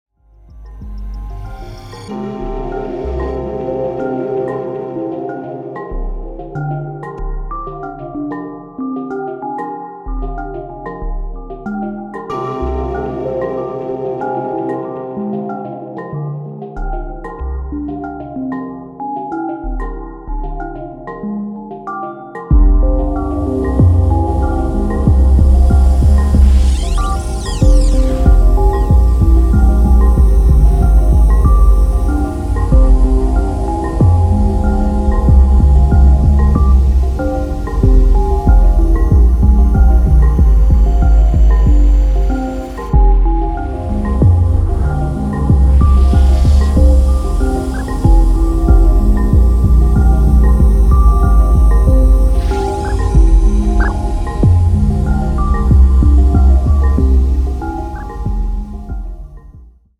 House Techno Bass Breaks